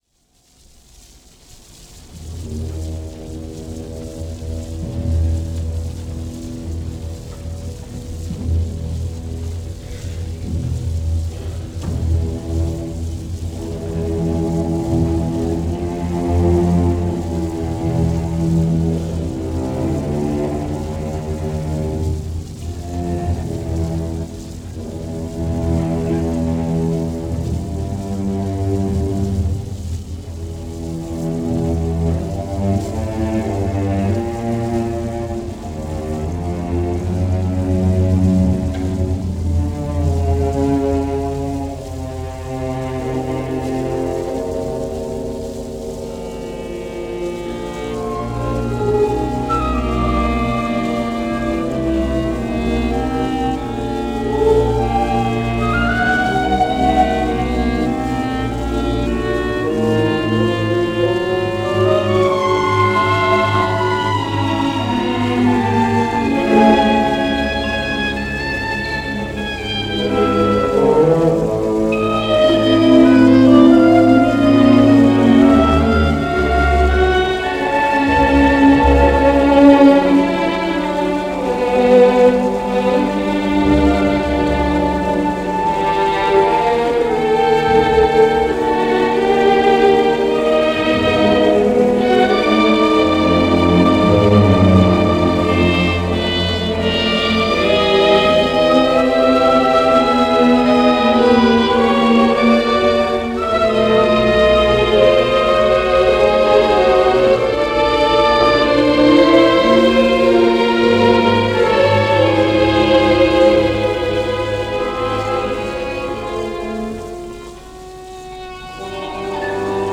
for Orchestra
played in this broadcast recording